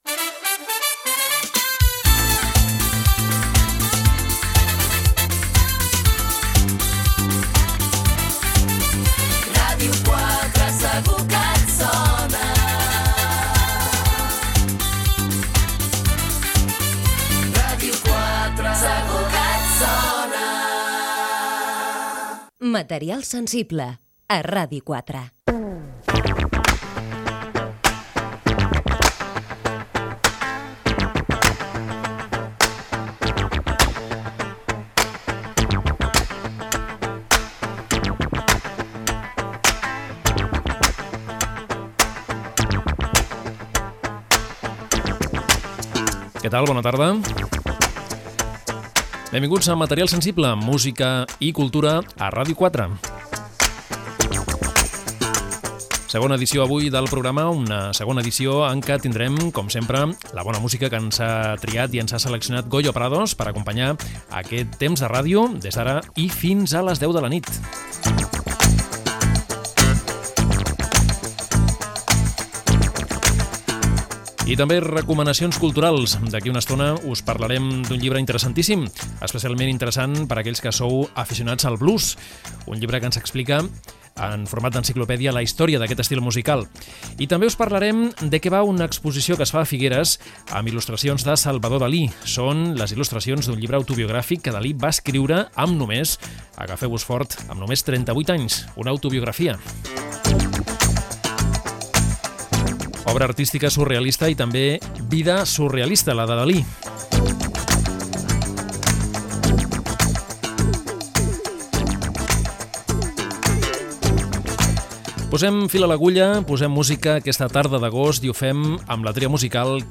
Indicatiu de l'emissora, inici del programa: presentació, sumari de continguts i tema musical